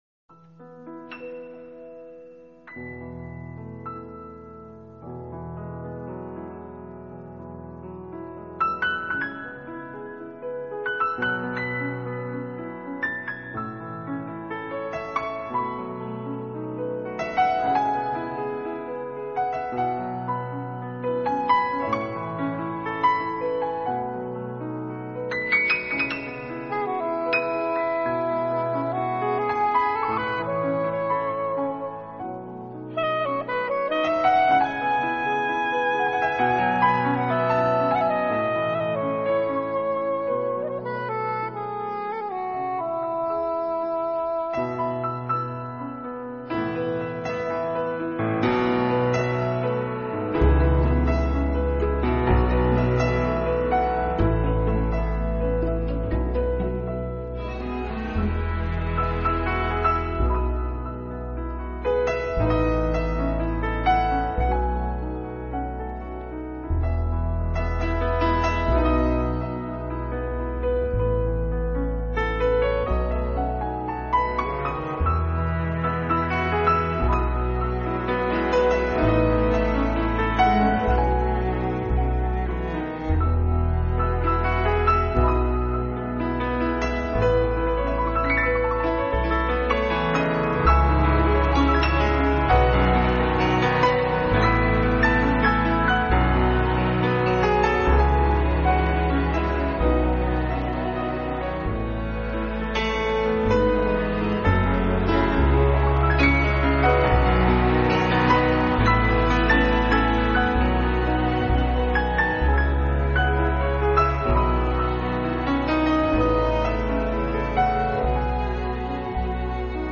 他们把这些原本流行的歌曲改编为带有明显爵士味道的纯音乐，
拥有了爵士音乐特有的浪漫，却没有爵士音乐的吃力和震荡，
这些作品却显得那么的舒缓和柔美，轻盈飘荡间，
如同雨天的细雨丝丝，漂浮旋转在你的脑海空间中。